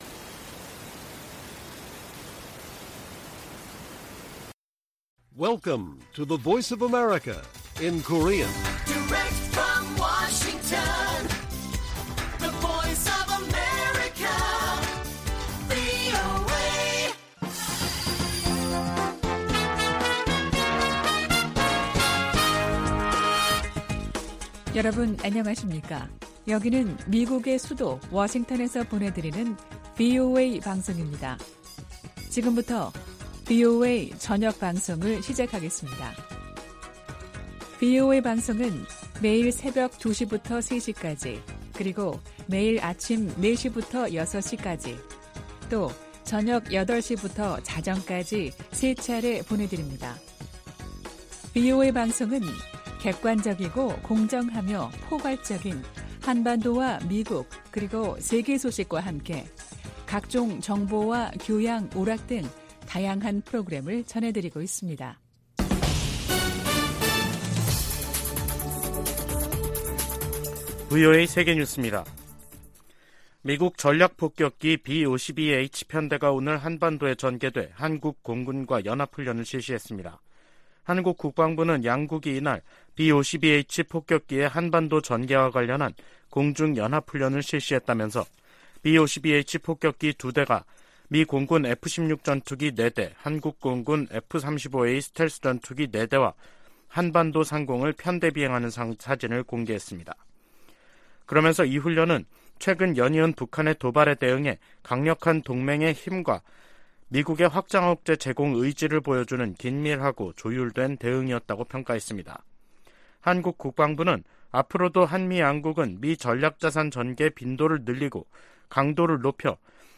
VOA 한국어 간판 뉴스 프로그램 '뉴스 투데이', 2023년 4월 14일 1부 방송입니다. 북한은 13일 발사한 '화성포-18형'이 고체연료를 사용한 신형 대륙간탄도미사일(ICBM)이라고 다음날인 14일 밝혔습니다. 북한이 핵 공격을 감행하면 김정은 정권의 종말을 초래할 것이라고 미국과 한국 국방당국이 경고했습니다. 북한이 우주 사업을 적극 추진하겠다고 밝힌 데 대해 미 국무부는 안보리 결의 위반 가능성을 지적했습니다.